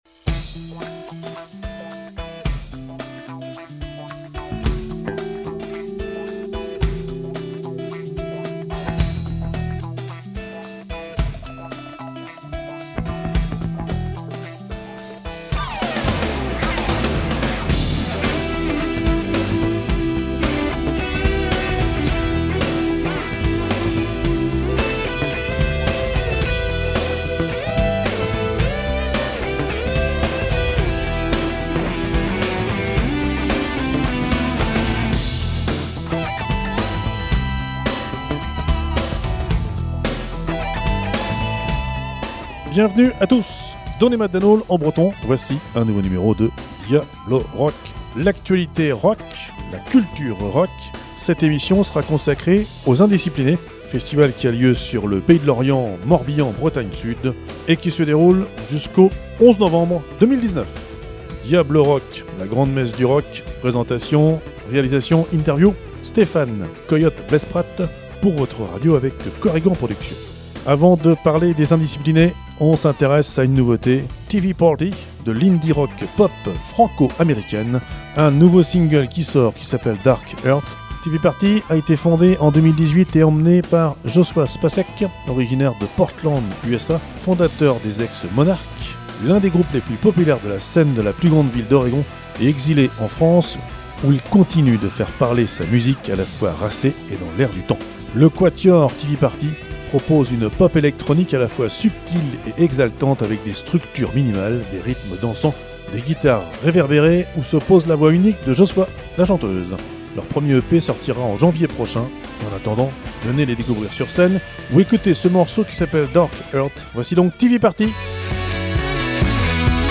Présentation, Musiques, Interviews